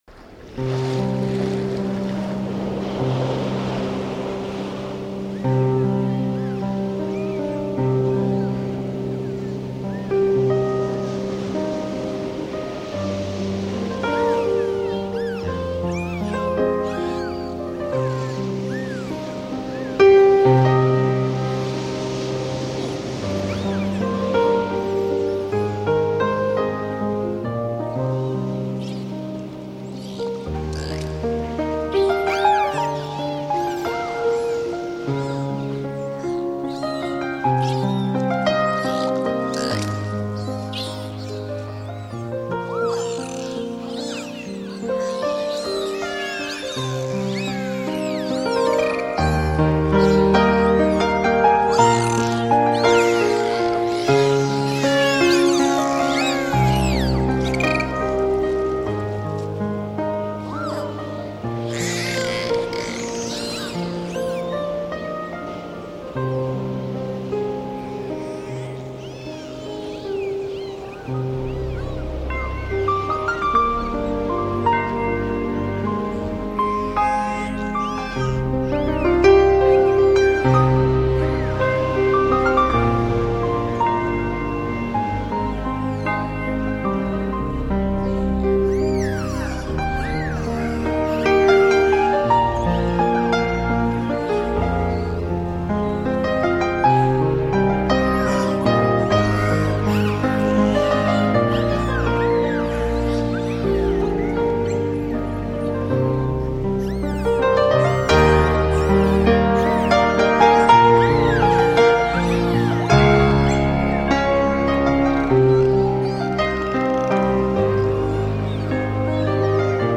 шум_морячайки
shum_moryachaqki.mp3